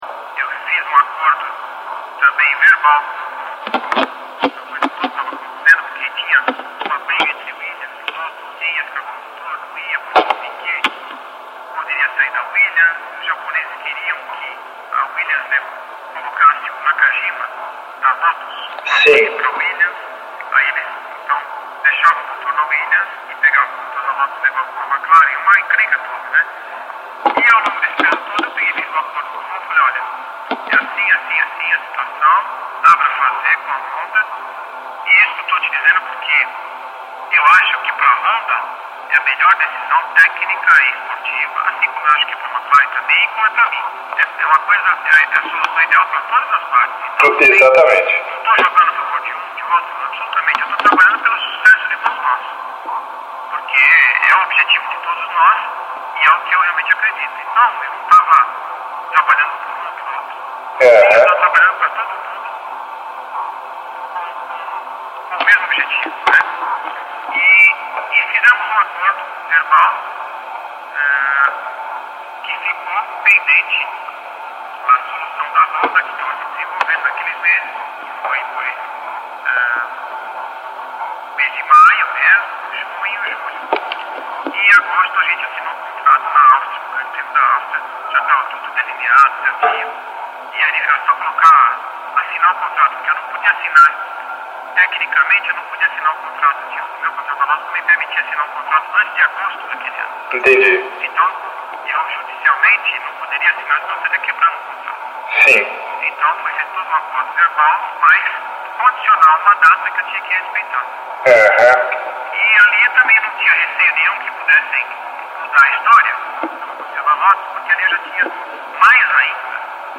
Senna – A entrevista 4 – GPTotal